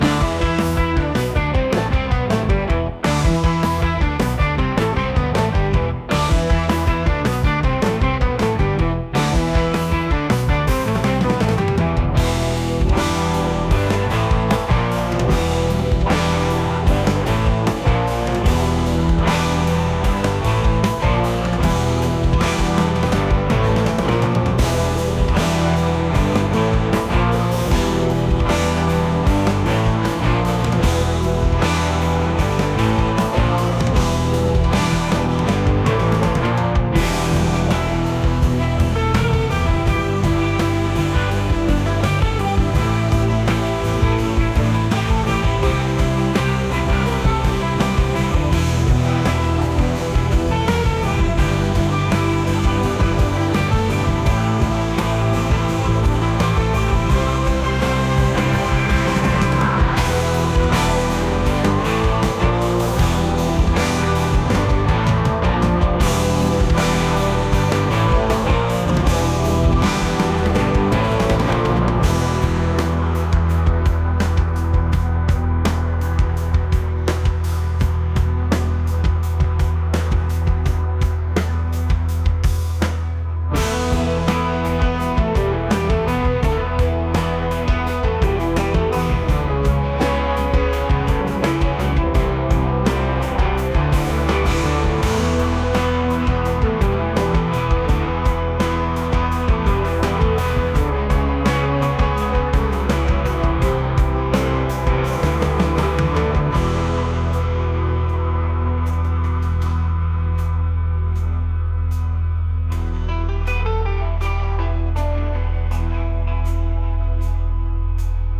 rock | psychedelic | ambient